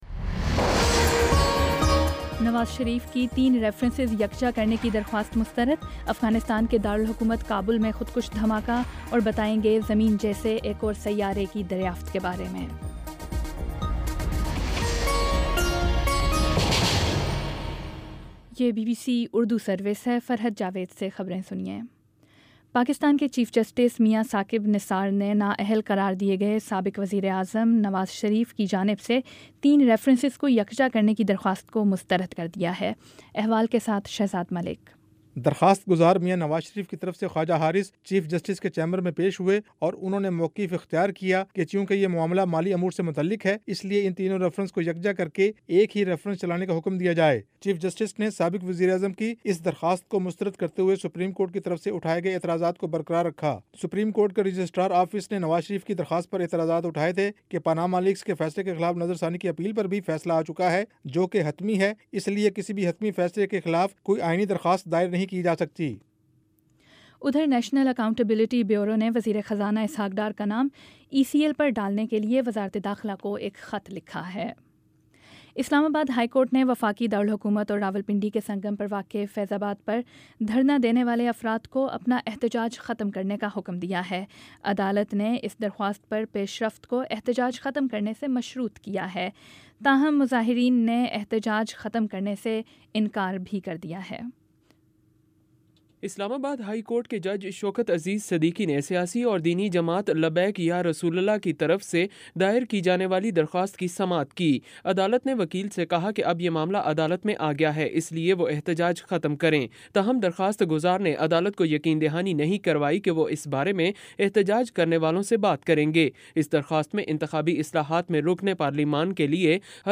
نومبر 16 : شام پانچ بجے کا نیوز بُلیٹن